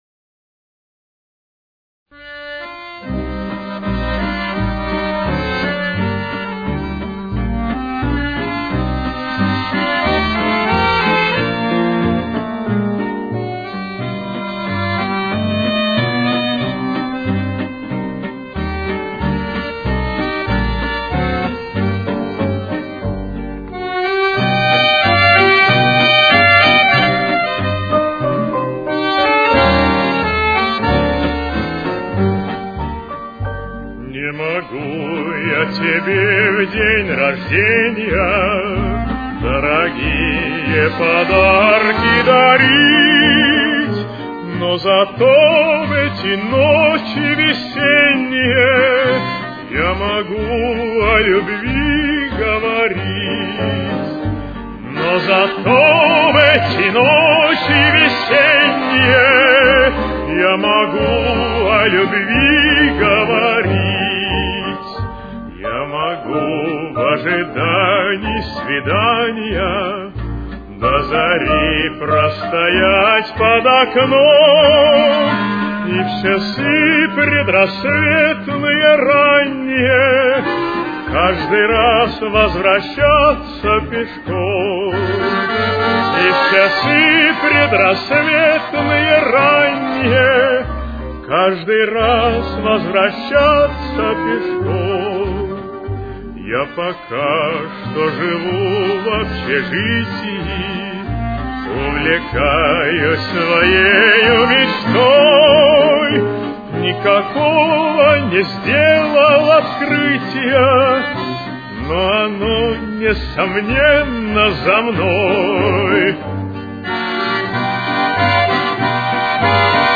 Темп: 94.